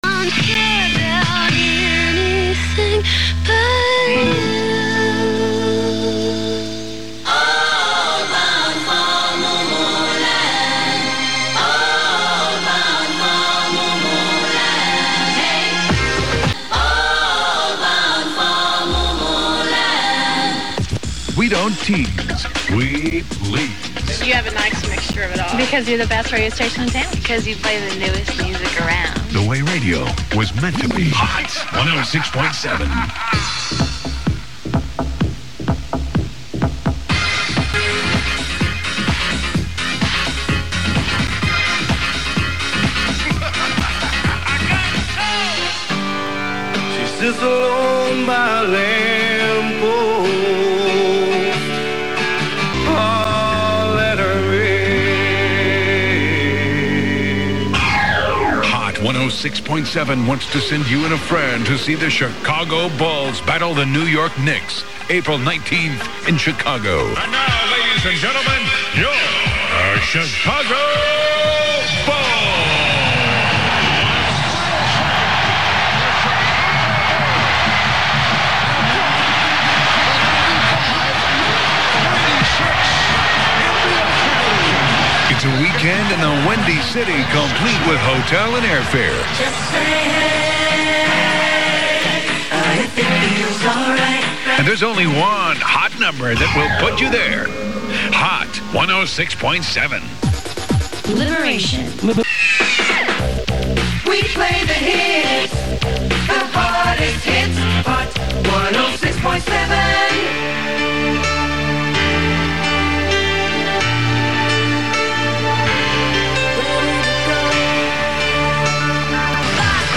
An uptempo, dance-friendly CHR is not necessarily what one would expect to find in a small Midwestern market, but that’s exactly what Hot 106.7 was at this time.